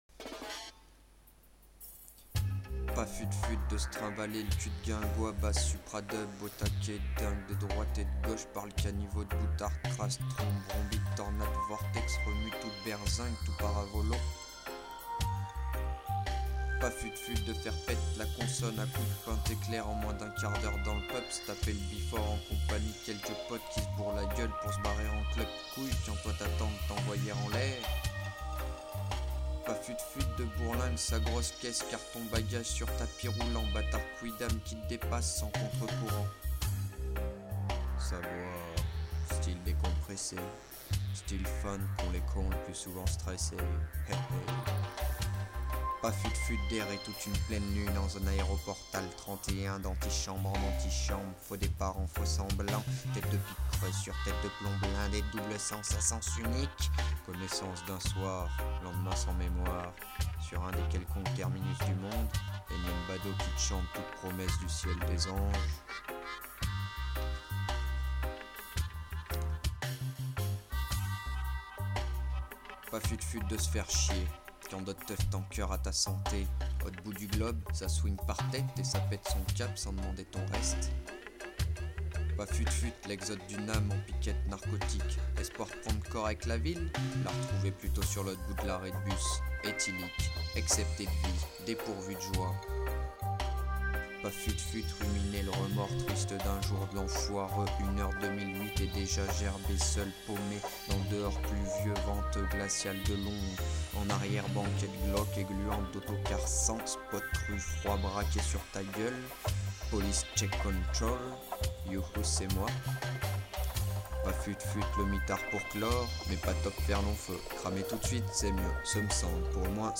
Pas fut fut, slam radiophonique à découvrir ou redécouvrir dès à présent!
pas-fut-fut-slam-avec-accord-son-voix.MP3